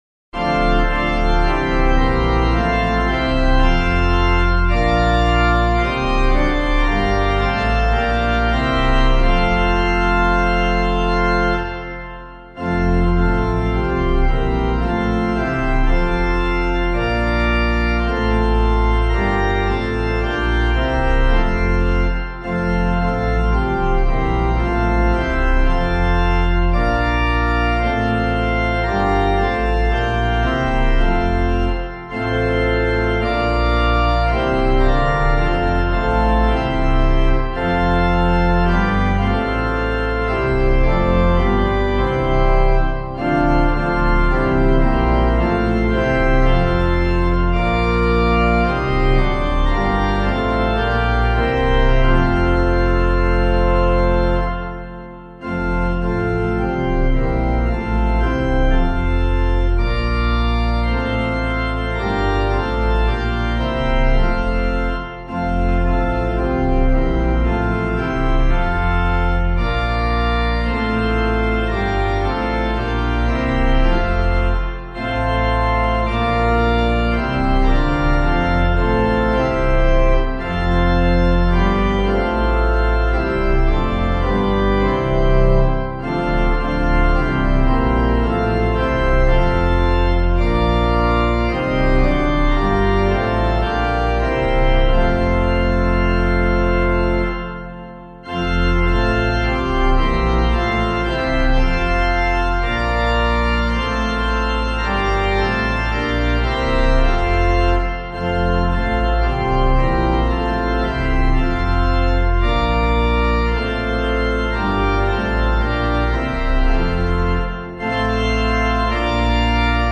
Hymn suitable for Catholic liturgy.